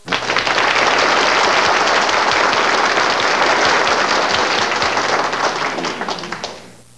Applause.snd